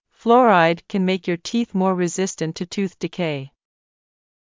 ﾌﾛｰﾗｲﾄﾞ ｷｬﾝ ﾒｲｸ ﾕｱ ﾃｨｰｽ ﾓｱ ﾚｼﾞｽﾀﾝﾄ ﾄｩ ﾄｩｰｽ ﾃﾞｨｹｲ